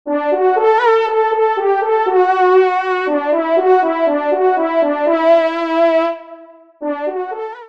FANFARE
Pupitre de Chant